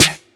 • Clear Acoustic Snare Sound D# Key 164.wav
Royality free acoustic snare sound tuned to the D# note. Loudest frequency: 4055Hz
clear-acoustic-snare-sound-d-sharp-key-164-vkn.wav